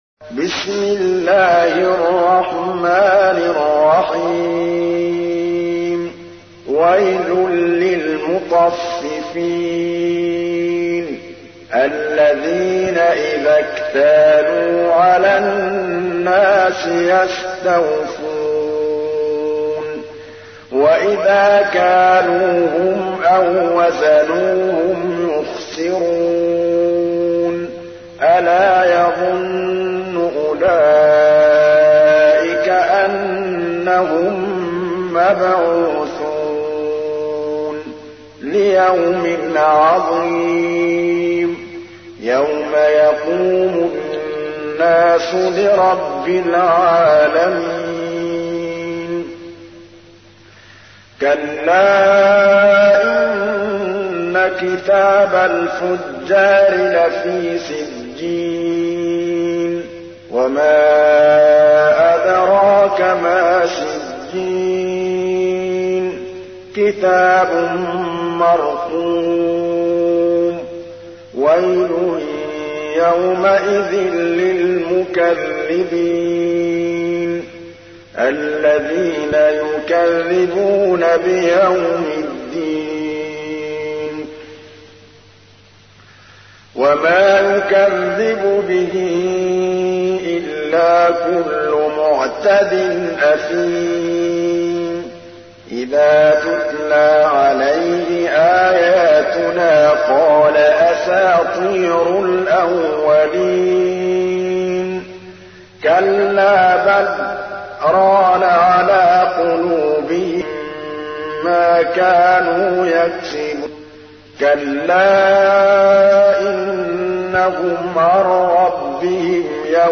تحميل : 83. سورة المطففين / القارئ محمود الطبلاوي / القرآن الكريم / موقع يا حسين